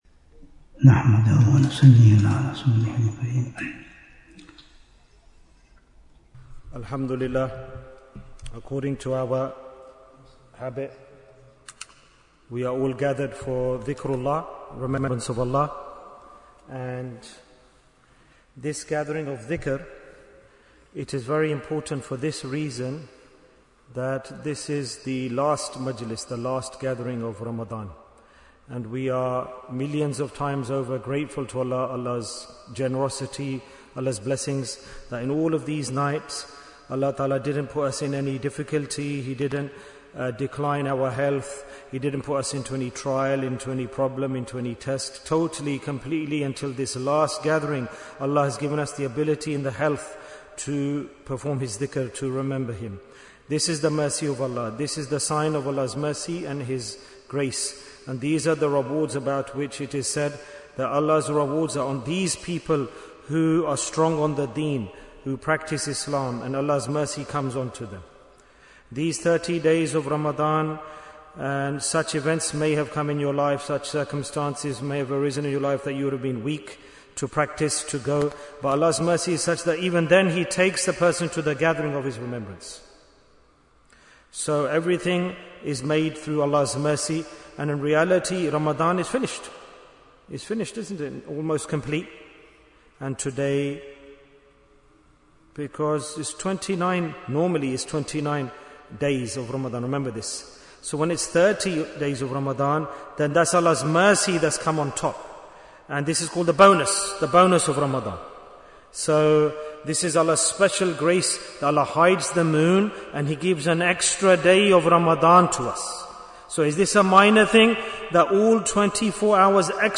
Jewels of Ramadhan 2026 - Episode 39 Bayan, 79 minutes18th March, 2026